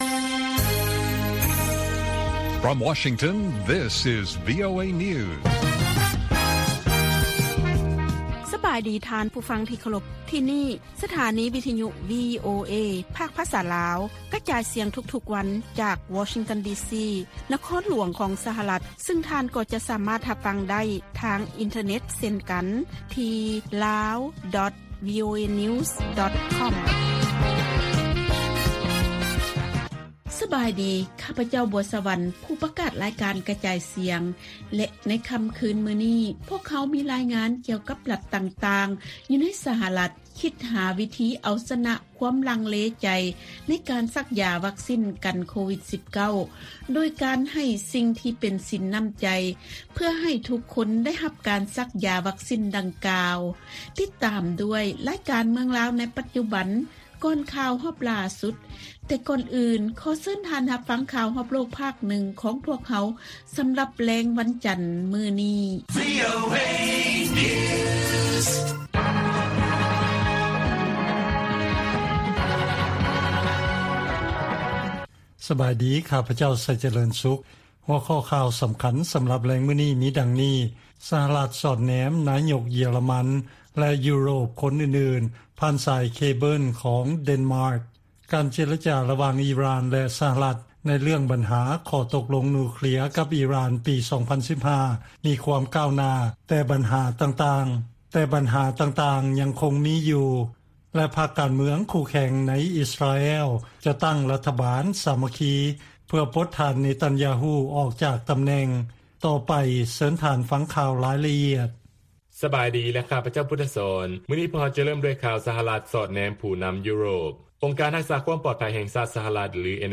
ວີໂອເອພາກພາສາລາວ ກະຈາຍສຽງທຸກໆວັນ. ຫົວຂໍ້ຂ່າວສໍາຄັນໃນມື້ນີ້ມີ: 1) ສະຫະລັດ ສອດແນມ ນາຍົກ ເຢຍຣະມັນ ແລະ ເຈົ້າໜ້າທີ່ຢູໂຣບຄົນອື່ນໆ ຜ່ານສາຍເຄເບິລຂອງເດັນມາກ .